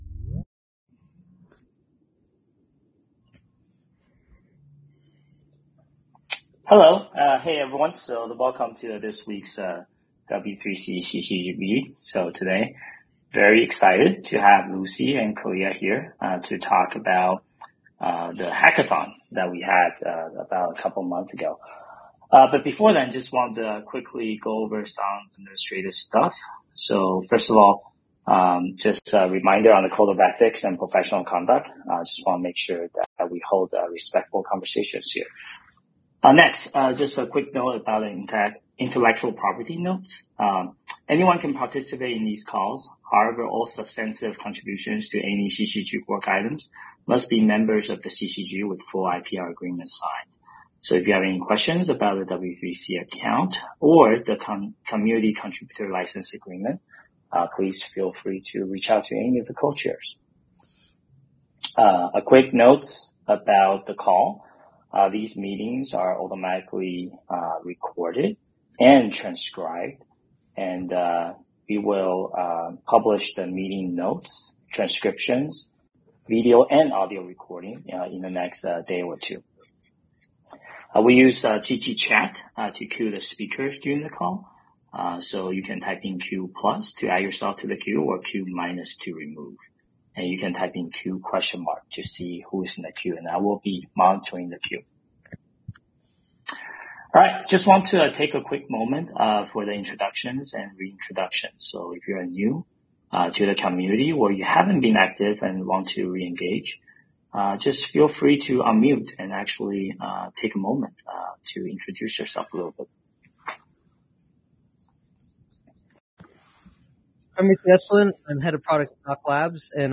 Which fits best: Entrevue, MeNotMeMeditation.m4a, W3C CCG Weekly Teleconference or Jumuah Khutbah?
W3C CCG Weekly Teleconference